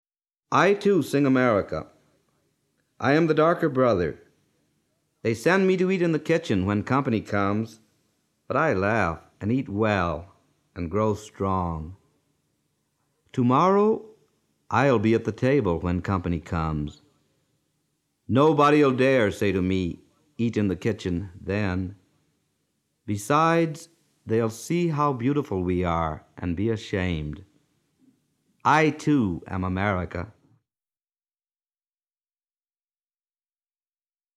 Pode o leitor interessado ouvir aqui o poema lido pelo  poeta.